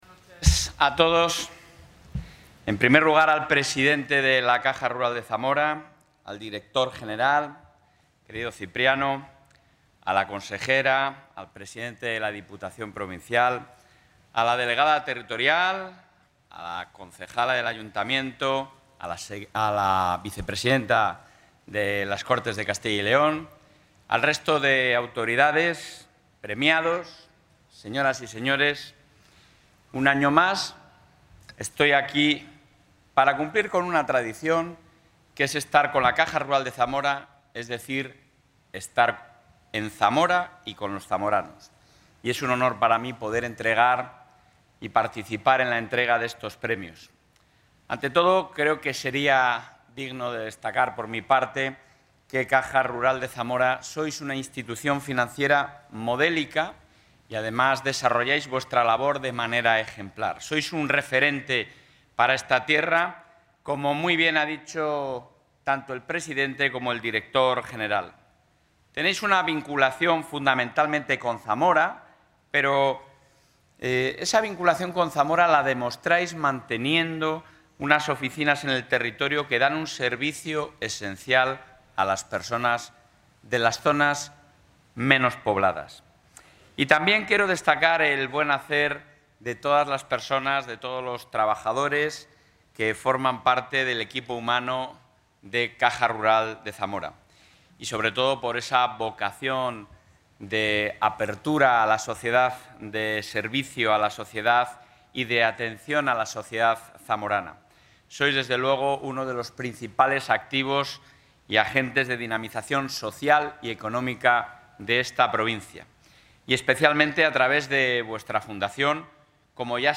Intervención del presidente.
El presidente de la Junta de Castilla y León ha reiterado en la entrega de los XXVI Premios Fundación Caja Rural de Zamora el compromiso de su Gobierno autonómico con la defensa de los intereses y el progreso económico de los zamoranos, así como con la mejora de los servicios en toda la provincia.